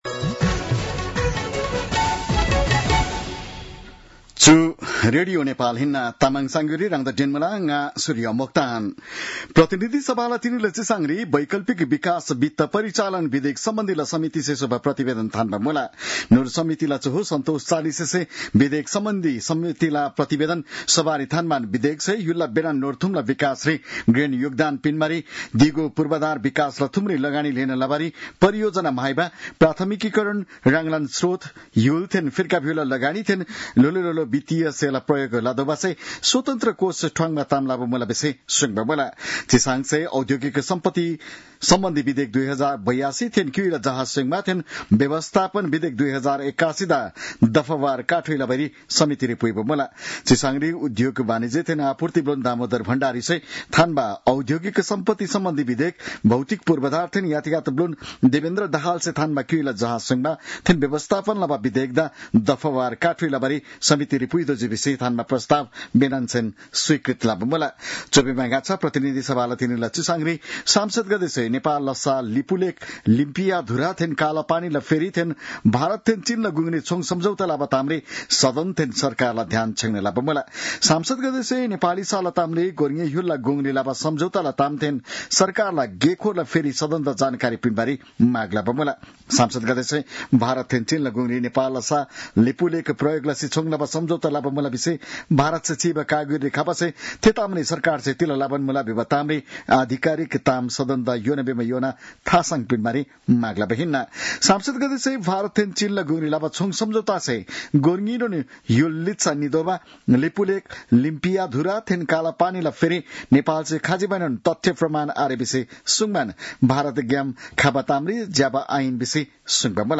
तामाङ भाषाको समाचार : ५ भदौ , २०८२